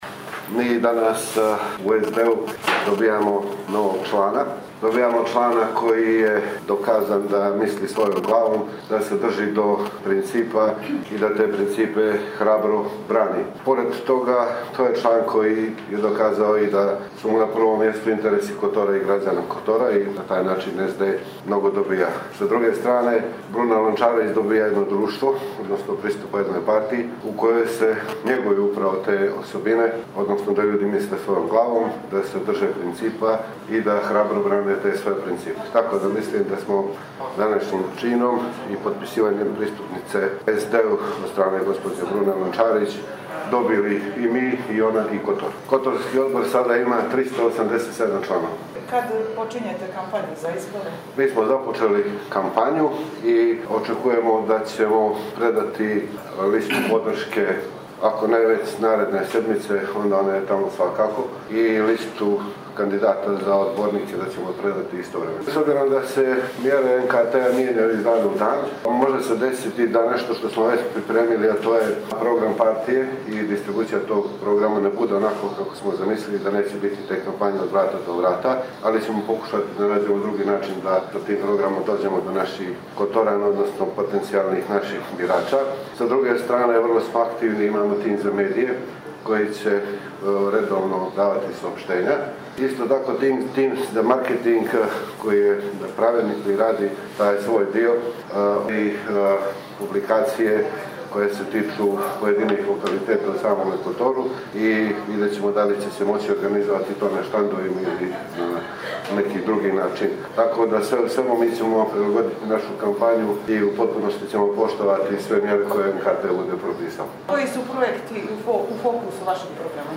sa današnje konferencije za medije
Sa-današnje-konferencije-za-medije.mp3